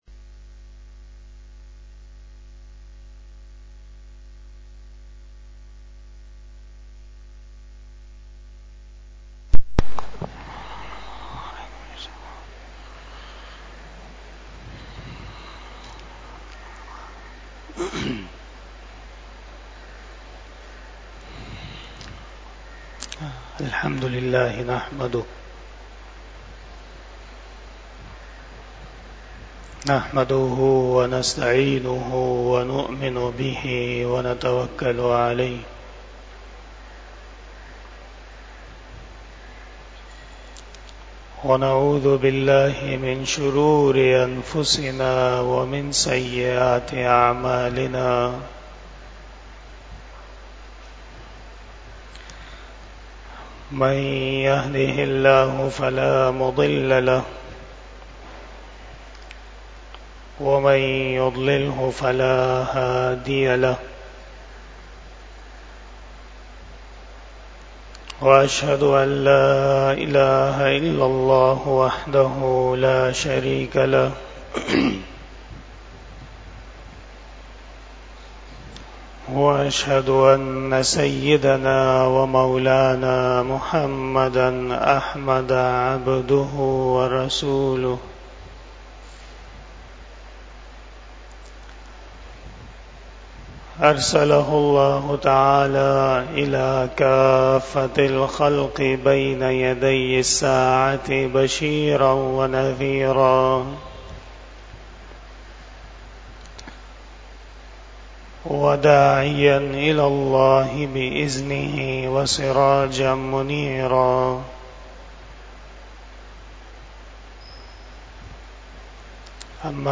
23 Bayan E Jummah 07 June 2024 ( 29 Zilqaadah 1445 HJ)